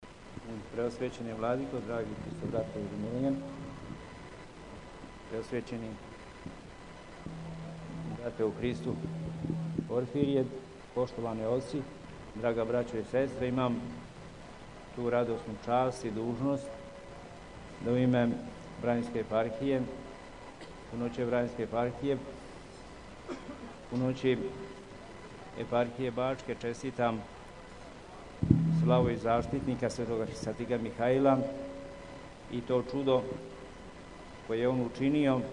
Овогодишњи литургијски спомен чуда које је Свети Архистратиг учинио у граду Хони (Колоси) одржано је у Сомбору, на Тргу Светог Георгија, код Светогеоргијевске цркве.
Беседа Епископа Пахомија: